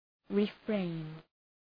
Shkrimi fonetik {rı’freın}